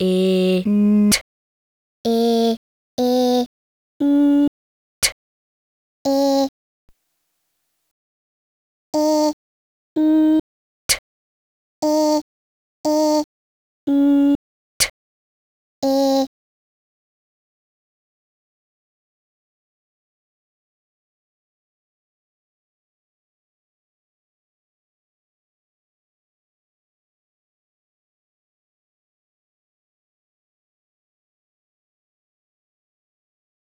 Großartig, wie hier der diktatorische Geist der Musik zutage tritt, der alle Worte und was ihnen an Betonung und anderer klanglicher Bedeutungsgewichtung gehört hat, unterwirft.